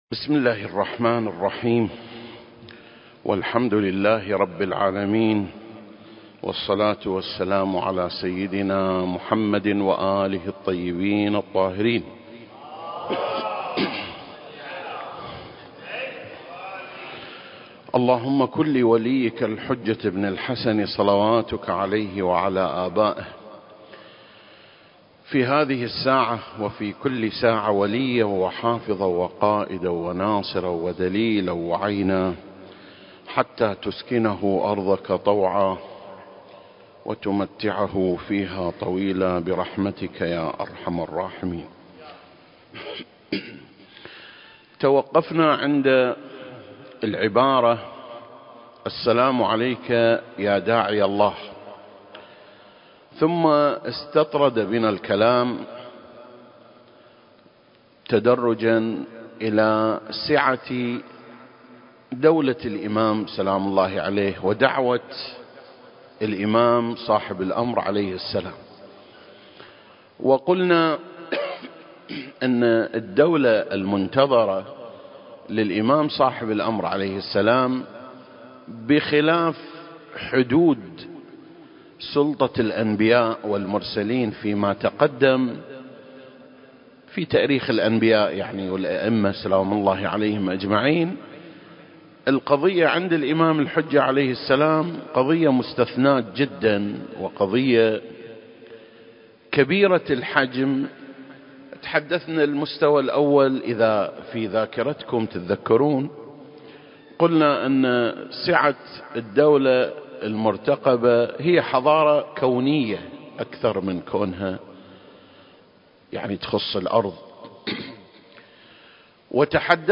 سلسلة: شرح زيارة آل ياسين (28) - سعة الدعوة المهدوية (2) المكان: مسجد مقامس - الكويت التاريخ: 2021